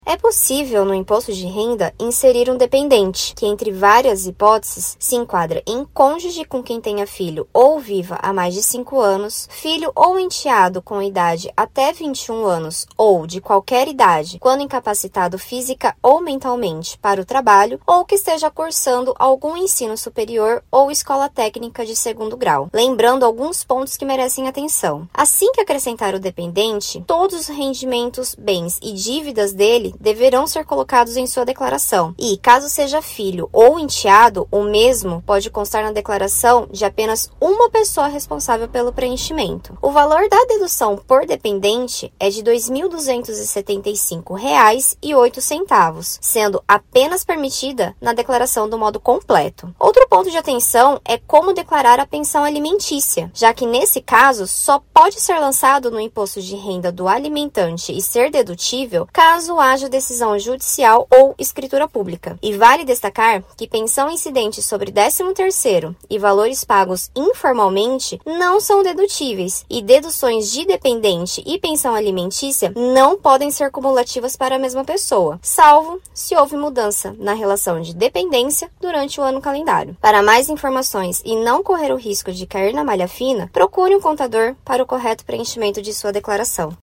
Mas como declarar os dependentes? A contadora